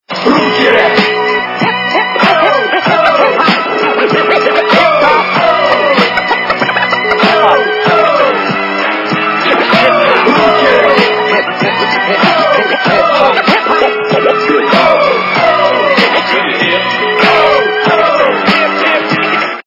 - рэп, техно